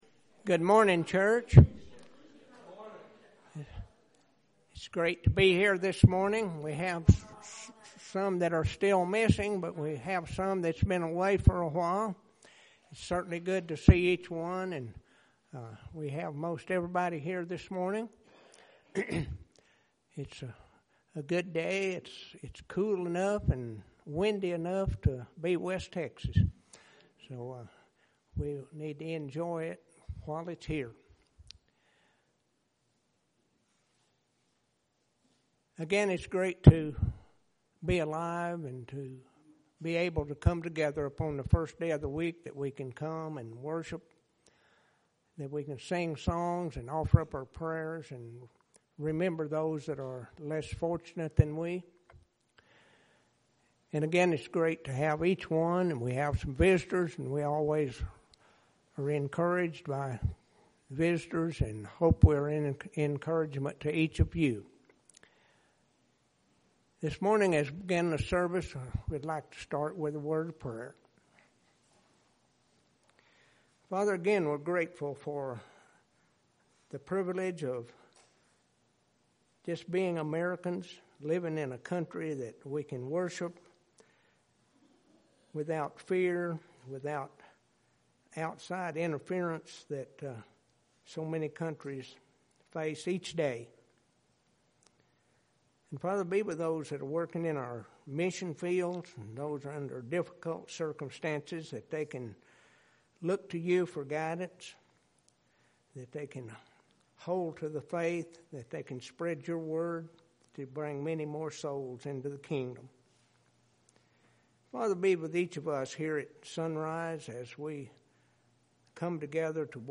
September 27th – Sermons